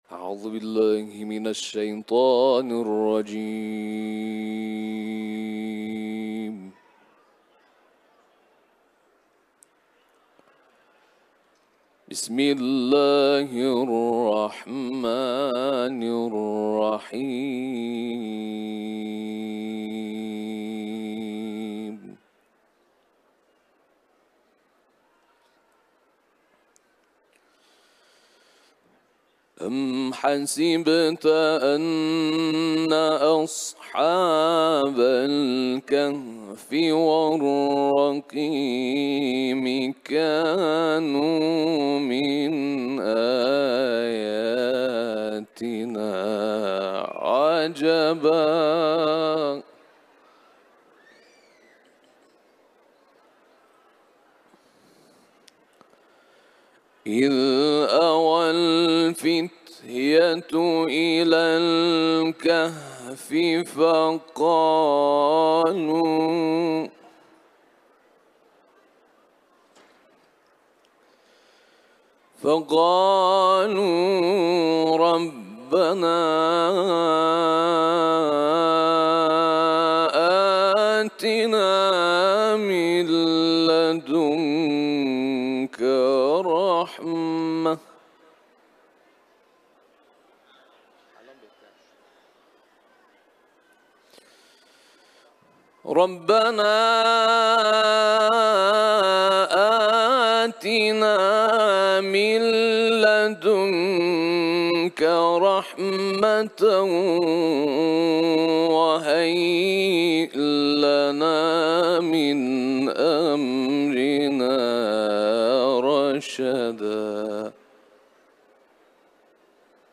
Kur’an-ı Kerim tilaveti